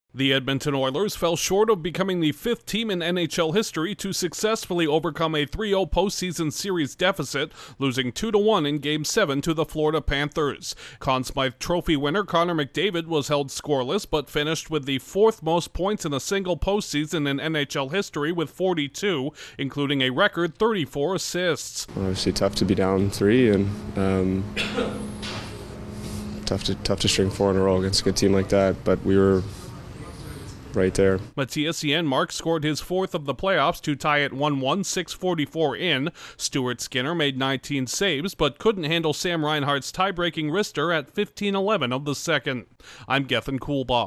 The Oilers fall just short of completing an historic run to a Stanley Cup title. Correspondent